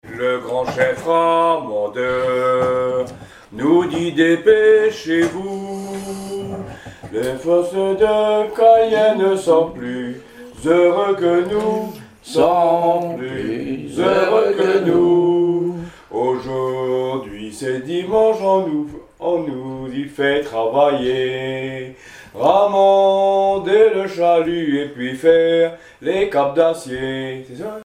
Genre strophique
Témoignage et chansons maritimes
Pièce musicale inédite